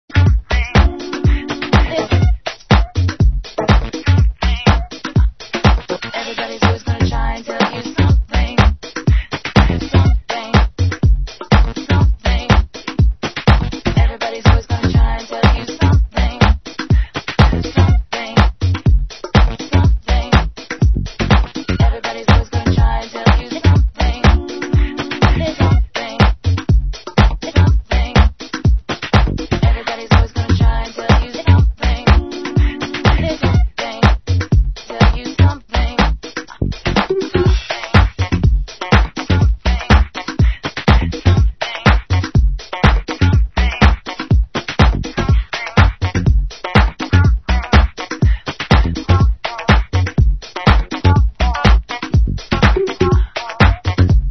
Arrow Tech-House Tune 2 Needs An ID!